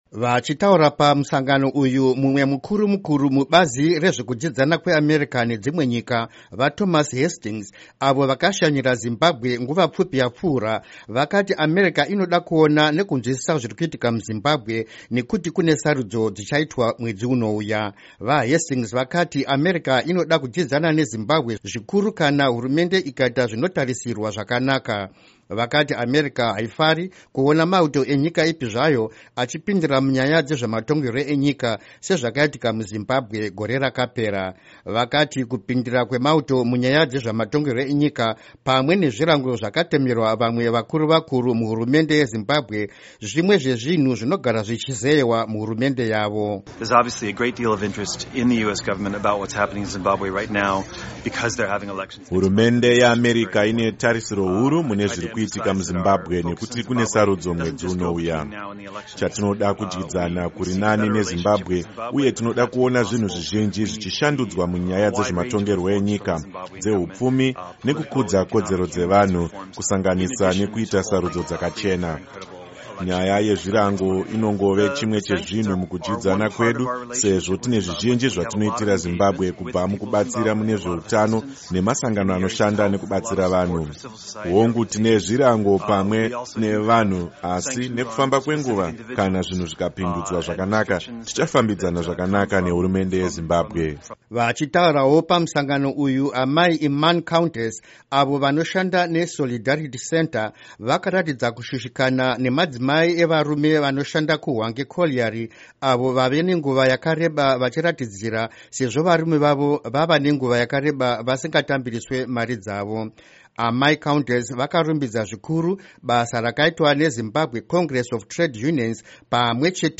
Nyanzvi munyaya dzematongerwo enyika dzakaungana muWashington nezuro dzichikurukura mamiriro akaita zvinhu muZimbabwe, pamwe nezvichaitika mushure musarudzo.